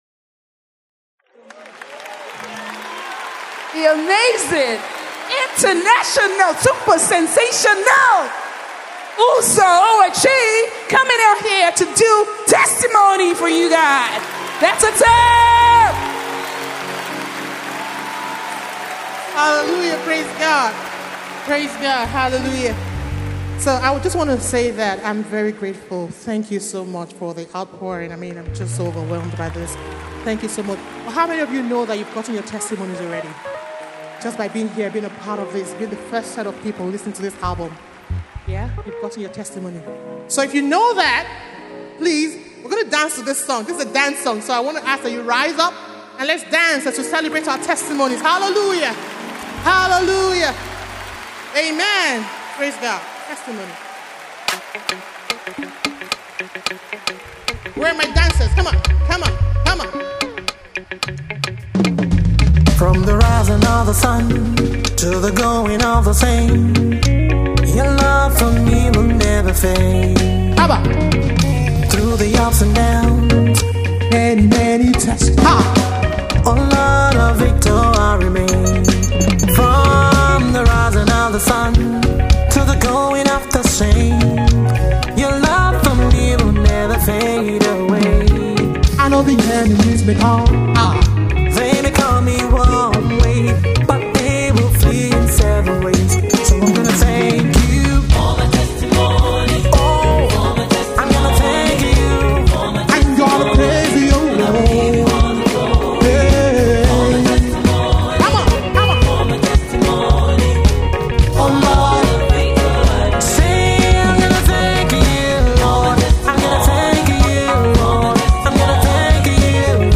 Gospel MusicVideo
” a vibrant, Afro-fusion praise song.
infused with infectious beats and passionate vocals